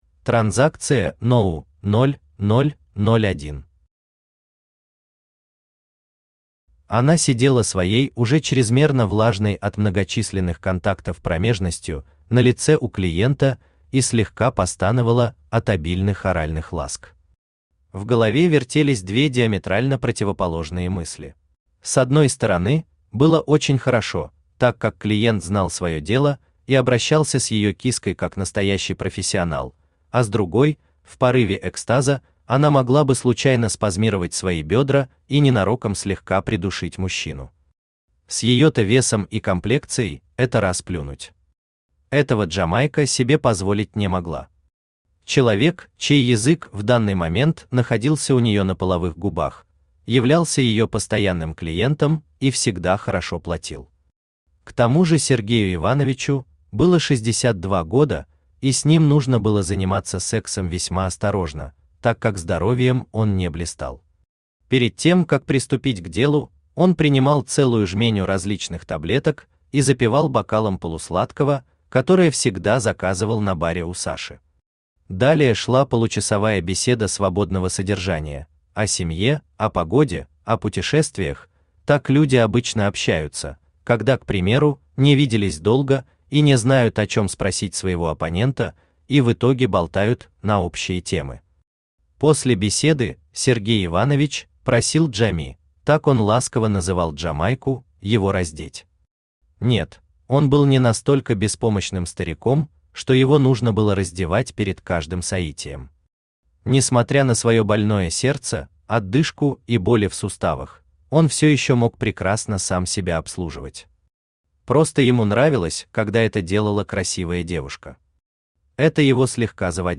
Aудиокнига Дайте две Автор Василий Козлов Читает аудиокнигу Авточтец ЛитРес.